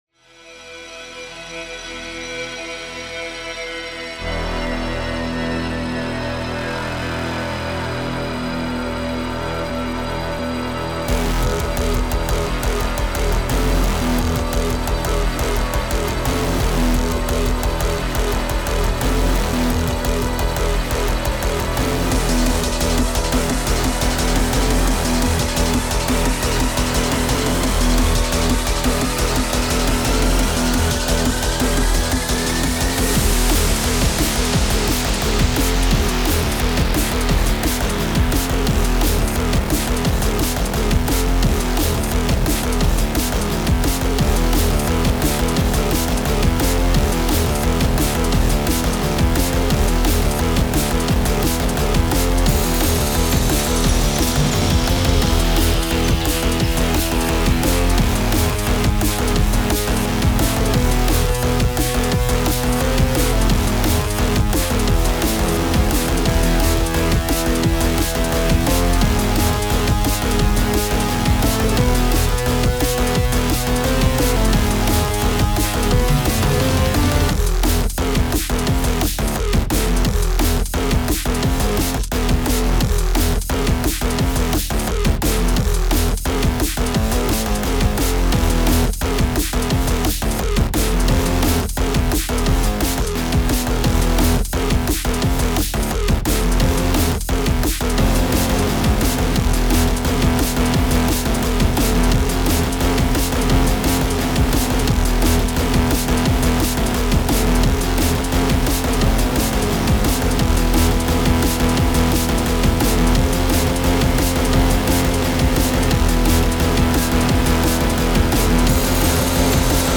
タグ: DnB EDM かっこいい 激しい/怒り 疾走感 コメント: ヘビーで疾走感のあるドラムンベース楽曲。